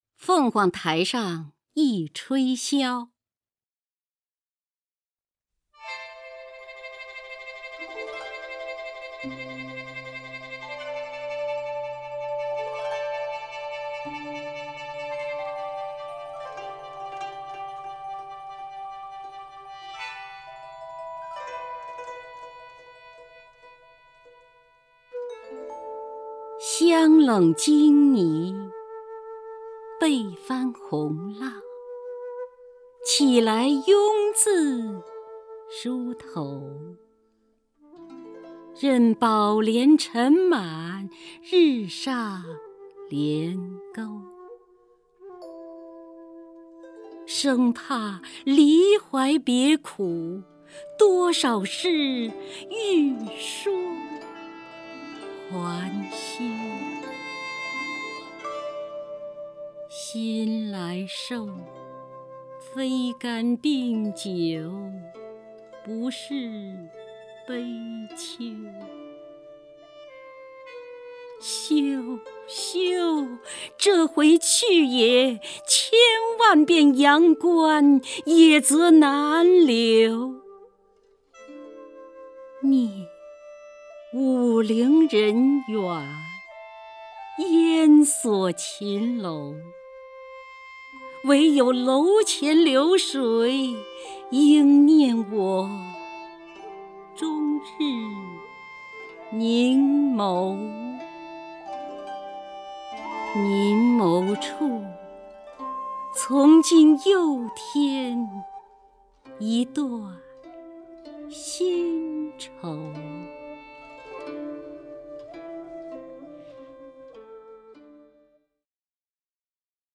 首页 视听 名家朗诵欣赏 姚锡娟
姚锡娟朗诵：《凤凰台上忆吹箫·香冷金猊》(（南宋）李清照)